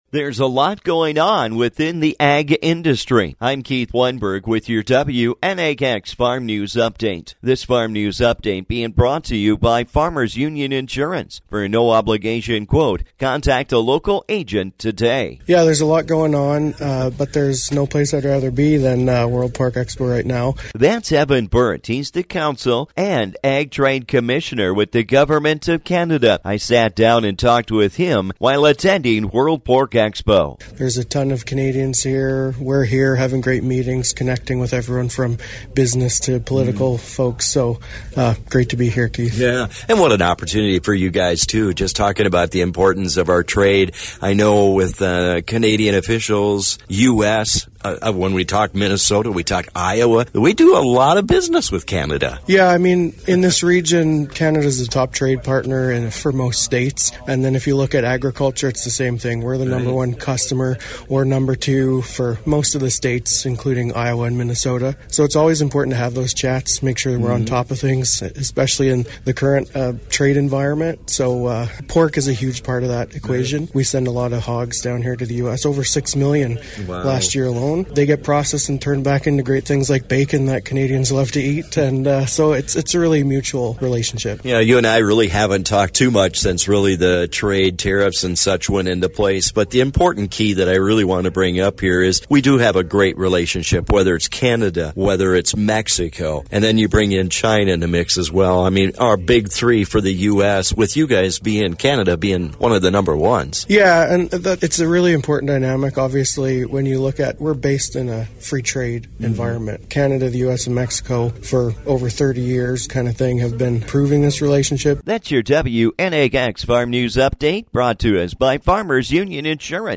Keeping our Trade Doors open during these uncertain times. I talk with the Government of Canada about these Trade Doors.